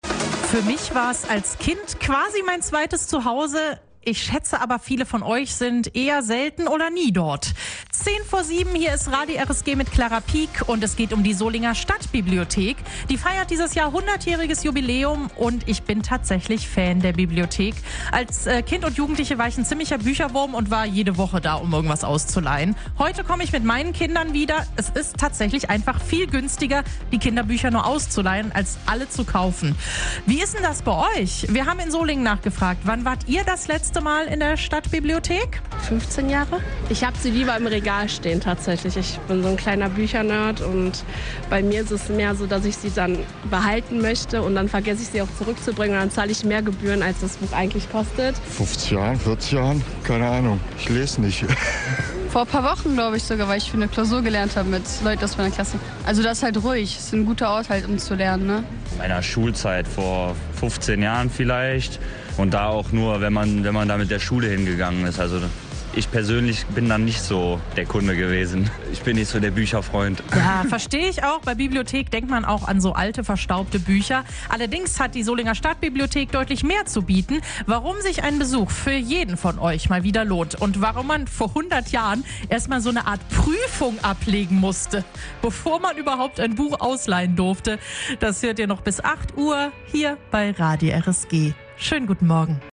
In der RSG-Morgenshow haben wir Solinger gefragt, wann sie das letzte Mal in der Stadtbibliothek waren, und wir haben euch erzählt, warum sich ein Besuch dort auf jeden Fall mal wieder lohnt.